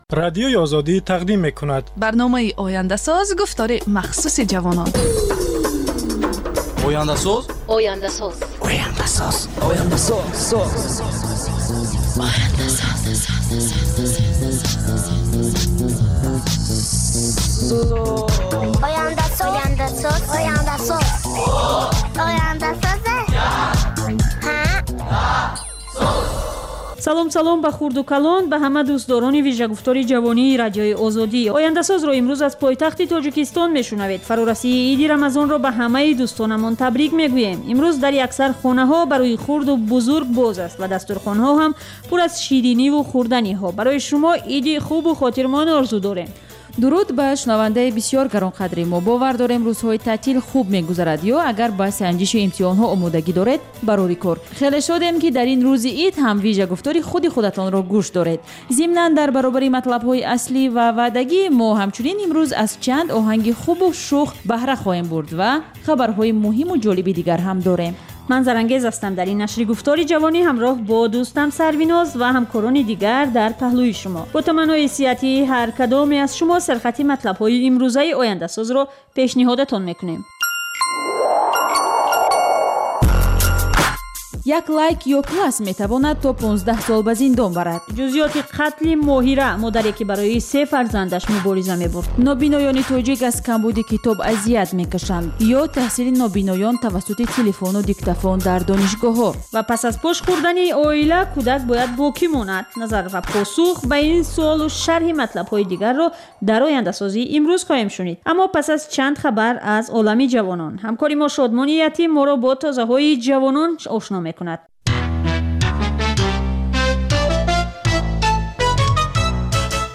"Ояндасоз" барномаи вижаи ҷавонон, ки муҳимтарин масоили сиёсӣ, иқтисодӣ, иҷтимоӣ ва фарҳангии Тоҷикистону ҷаҳонро аз дидгоҳи худи онҳо ва коршиносон таҳлил ва баррасӣ мекунад. Бар илова, дар ин гуфтор таронаҳои ҷаззоб ва мусоҳибаҳои ҳунармандон тақдим мешавад.